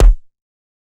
Kick (11).wav